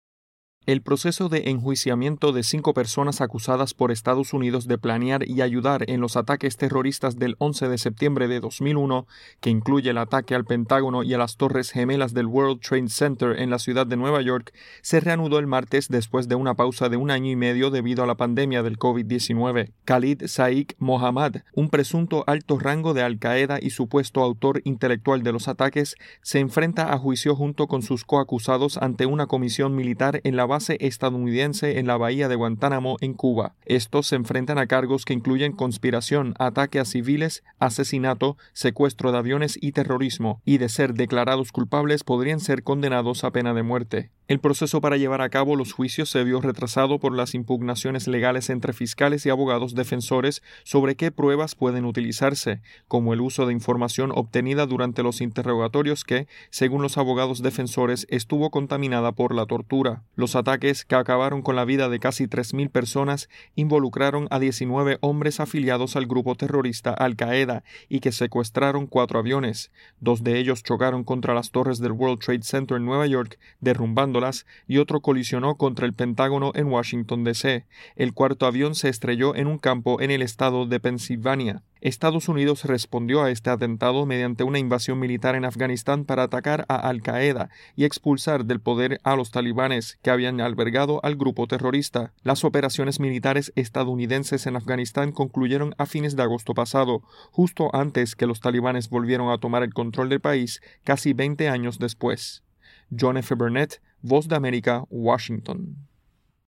Un bombero que estuvo en primera línea durante los ataques del 11 de septiembre de 2001 o 9/11, como son conocidos, narra cómo cambió su vida con los ataques terroristas, de los que próximamente se cumplirán 20 años.